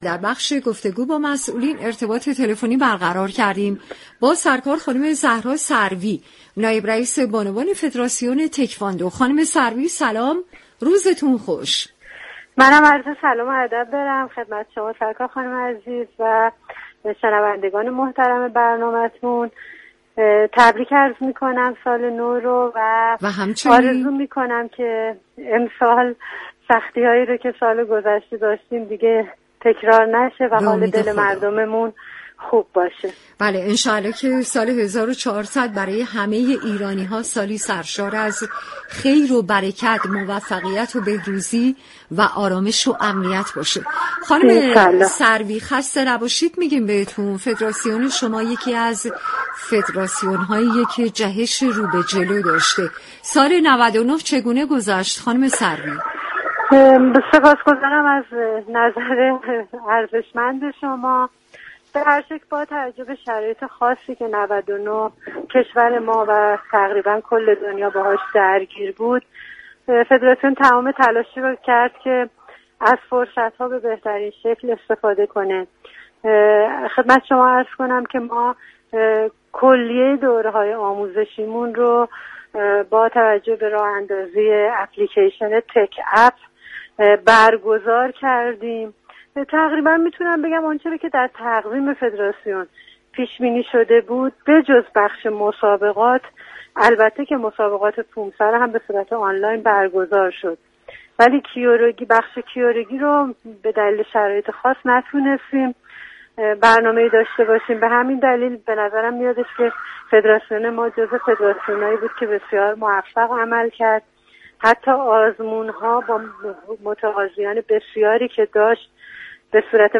شما می توانید از طریق فایل صوتی پیوست شنونده این گفتگو باشید. ویژه برنامه نوروزی«ایرانداخت» به مصاحبه با بانوان افتخارآفرین ورزش ایران می پردازد.